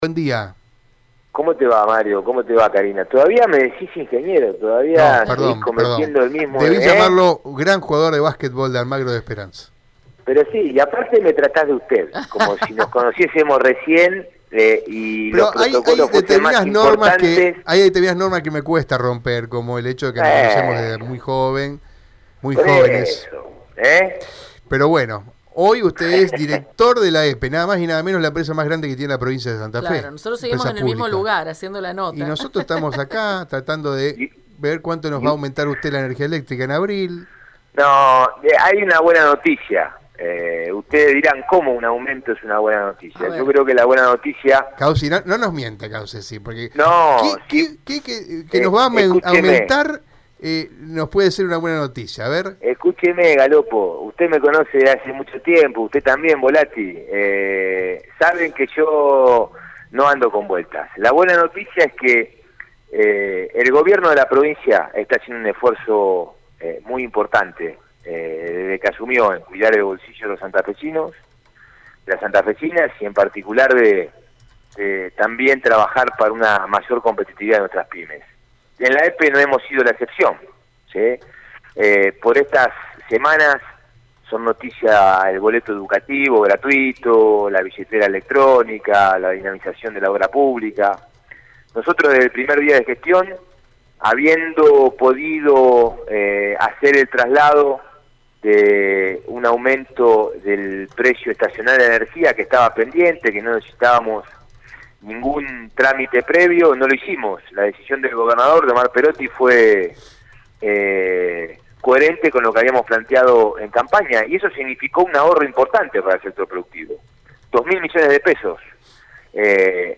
Las tarifas de la EPE será retocadas según admitió el Presidente del Directorio de la empresa, Mauricio Caussi, en diálogo esta mañana con el programa “Informa-Dos”